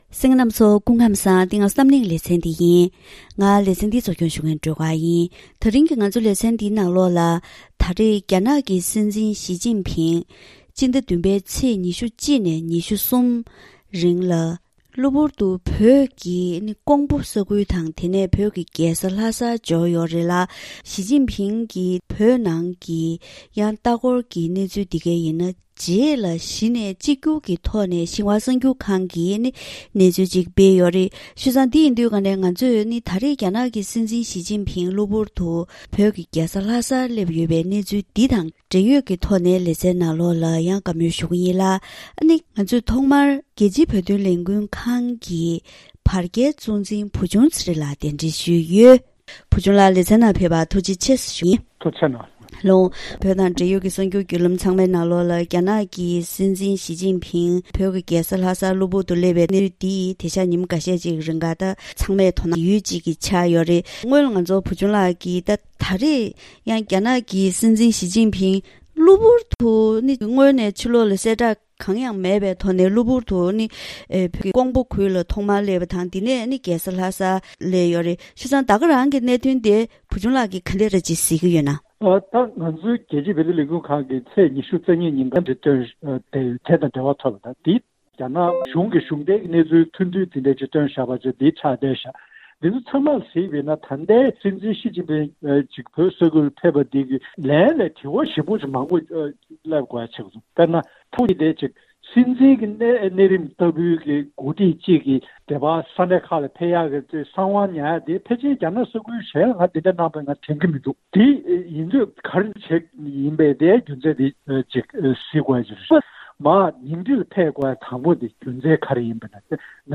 ད་རིང་གི་གཏམ་གླེང་ཞལ་པར་ལེ་ཚན་ནང་ཉེ་ཆར་རྒྱ་ནག་གི་སྲིད་འཛིན་ཞི་ཅིན་ཕིང་བོད་ཀྱི་རྒྱལ་ས་ལྷ་སར་གློ་བུར་དུ་སླེབས་པ་དང་། བོད་མི་ཚོས་རྒྱ་ནག་དམར་ཤོག་གི་རྗེས་སུ་འབྲངས་དགོས་པ། བོད་ཀྱི་ད་ལྟའི་འཚོ་བ་འདི་དམར་ཤོག་གཞུང་གིས་སྤྲད་སྐོར་སོགས་གླེང་ཡོད་ན་ཡང་བོད་ནང་གང་ཅིའི་རང་དབང་ཐོབ་ཐང་མེད་པ་འདི་ཡོངས་ཁྱབ་ཆགས་ཡོད་ཅིང་། བོད་འདི་རྒྱ་མི་ཡུལ་སྐོར་བ་སྐྱོད་ས་ཞིག་དང་བོད་མི་ནི་ཡུལ་སྐོར་གྱི་ཞབས་ཞུ་བྱེད་མཁན་ལྟ་བུར་གྱུར་བཞིན་ཡོད་པ་སོགས་ཞི་ཅིན་ཕིང་བོད་ལ་སྐྱོད་པའི་སྐོར་གྱི་གནས་ཚུལ་འདིར་འབྲེལ་ཡོད་དང་ལྷན་དུ་བཀའ་མོལ་ཞུས་པ་ཞིག་གསན་རོགས་གནང་གནང་།